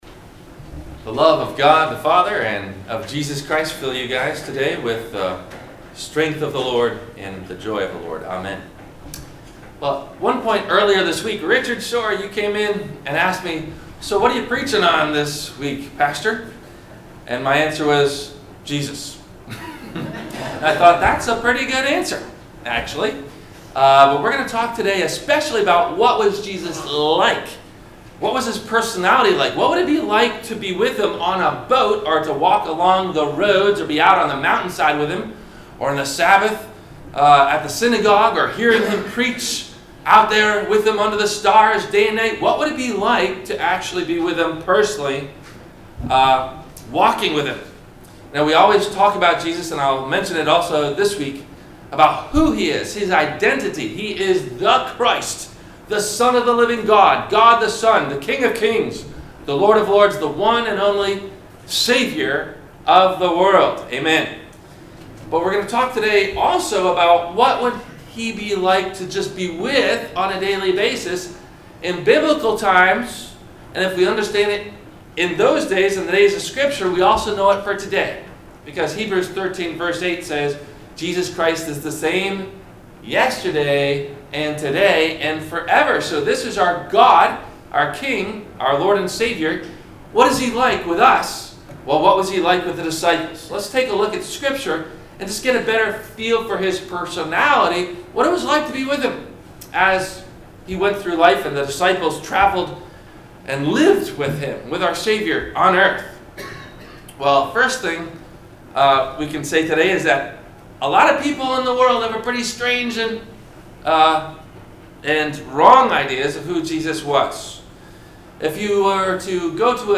Are We Putting Jesus in a Box? – Sermon – March 04 2018